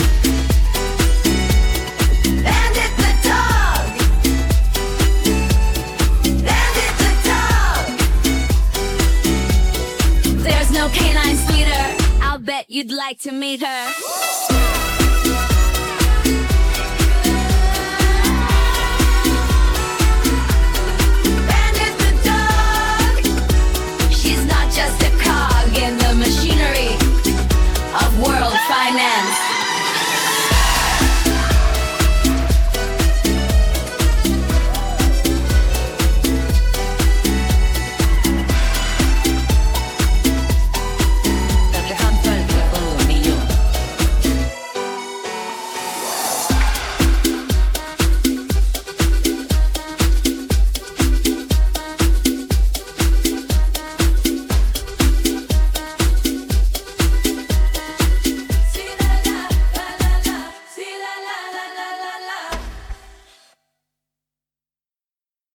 Instrumental Playoff